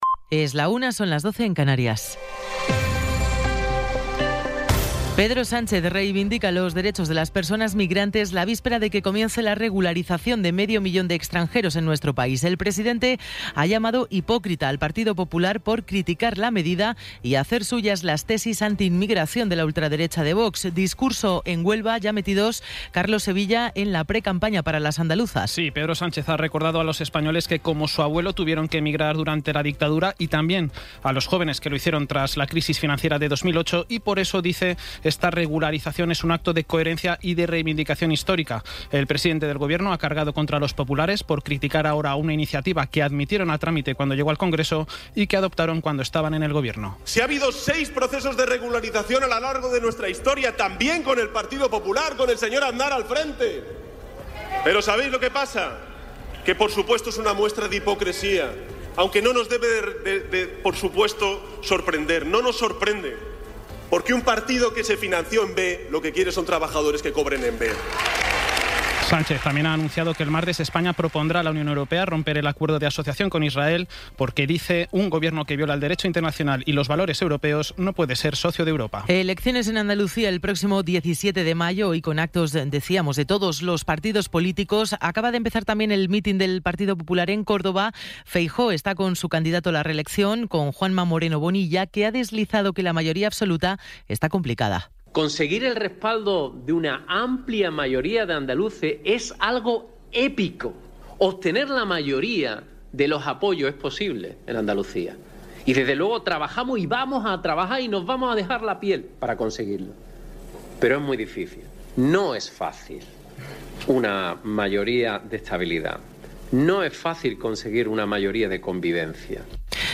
Resumen informativo con las noticias más destacadas del 19 de abril de 2026 a la una de la tarde.